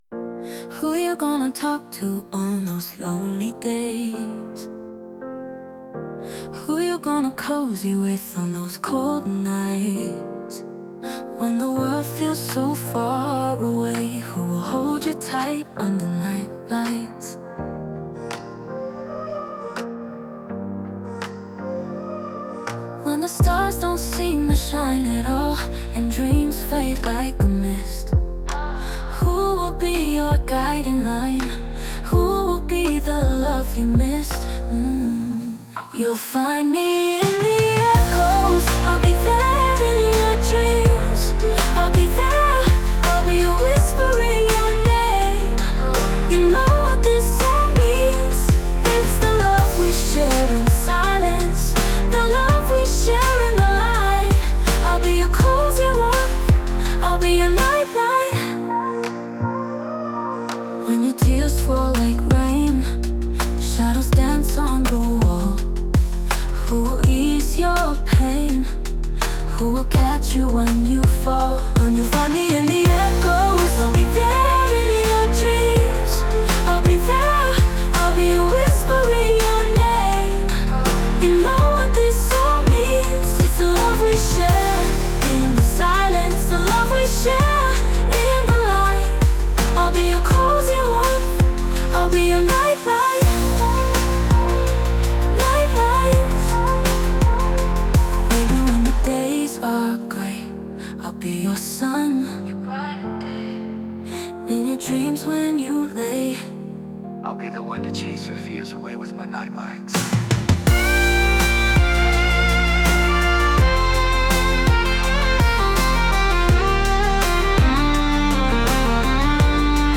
expressive lead vocals
who played keyboards on this recording.